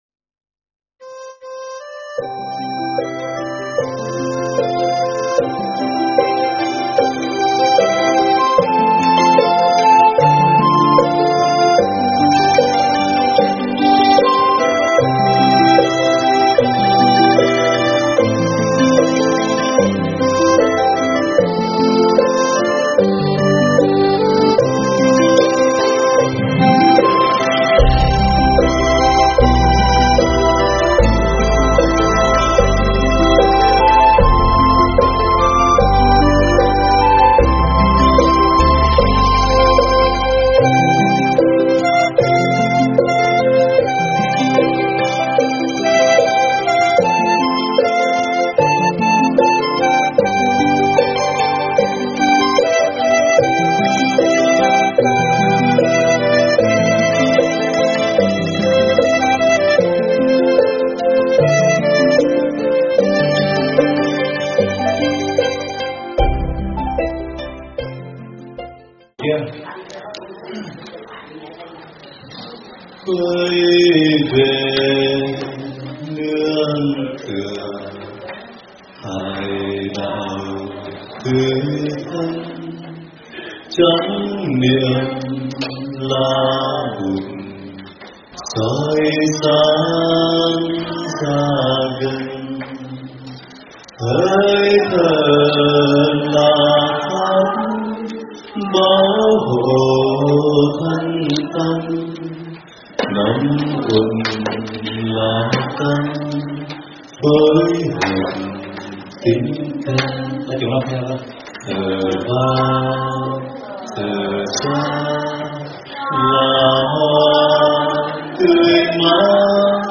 Nghe mp3 thuyết pháp Hộ Trì do Thầy Thích Pháp Hòa giảng tại Chùa Hoa Nghiêm, Virginia Ngày 21 tháng 9 năm 2019